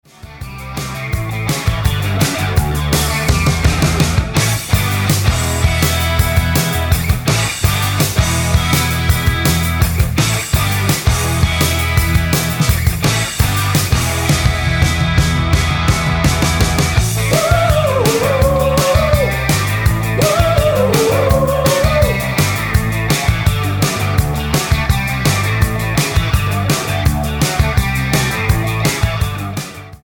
Tonart:A mit Chor
Die besten Playbacks Instrumentals und Karaoke Versionen .